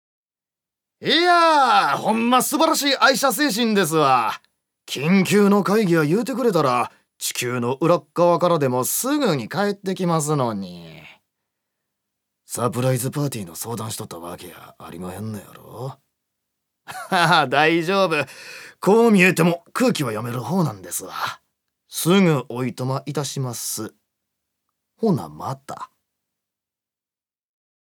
所属：男性タレント
セリフ３